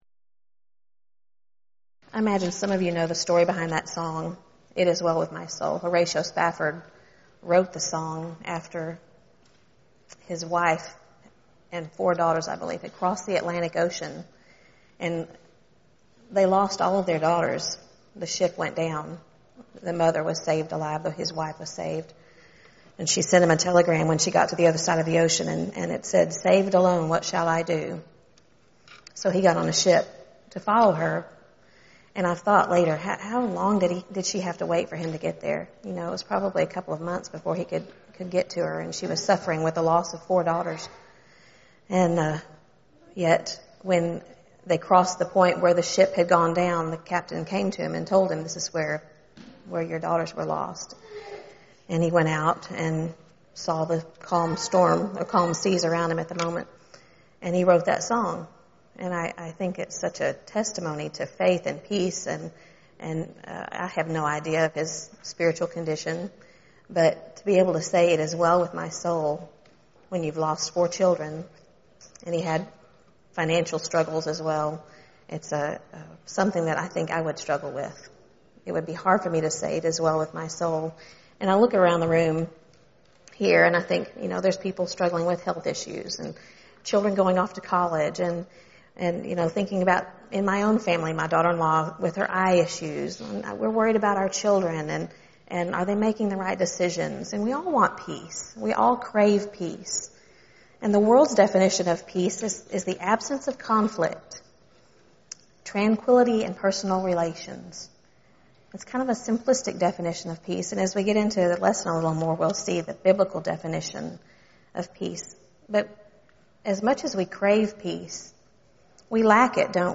Preacher's Workshop
Ladies Sessions